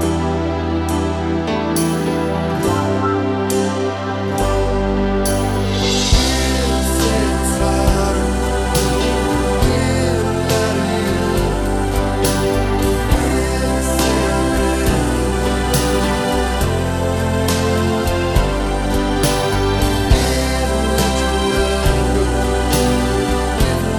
Three Semitones Down Pop (2000s) 3:39 Buy £1.50